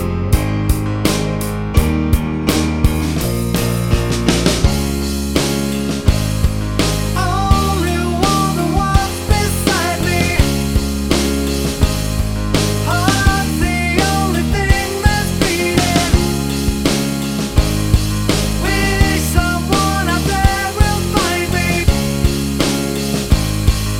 Minus All Guitars Rock 4:23 Buy £1.50